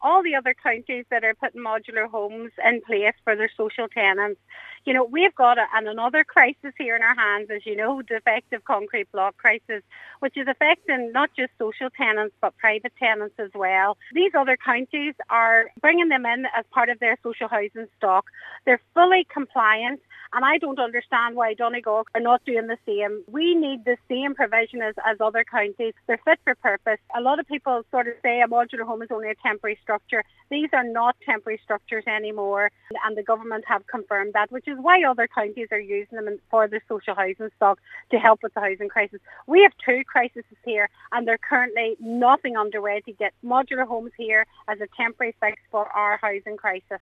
Cllr Beard told a special Donegal County Council meeting that while other counties are using modular homes to address their social housing needs, Donegal continues to resist, even though even more pressure will be put on the system once a remediation scheme is implemented for DCB affected social homes.